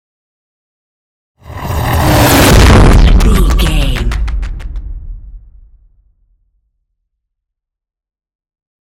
Scifi passby whoosh
Sound Effects
Atonal
futuristic
intense
pass by
car